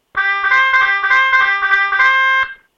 9 belklanken met regelbaar volume
-- M-E-6x0 belklank-keuzemogelijkheden (klik op bel):